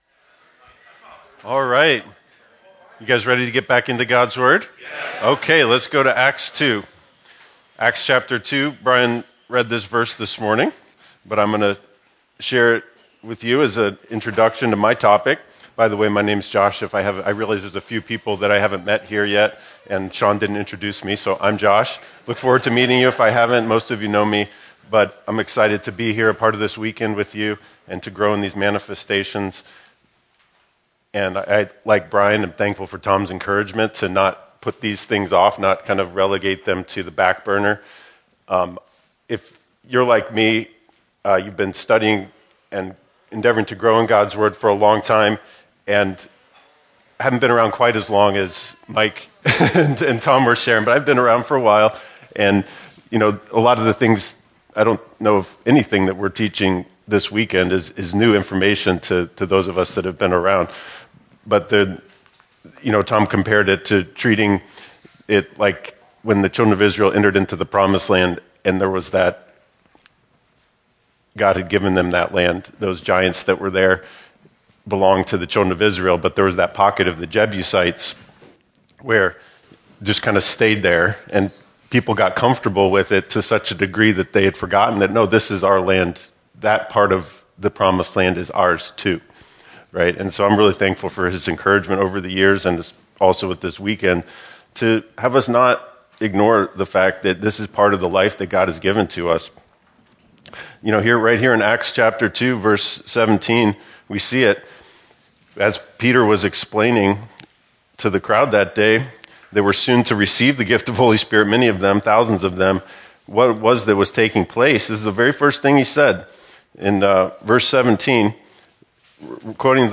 An introductory teaching on the revelation manifestations taken from the Men’s Weekend, “Greater Works”.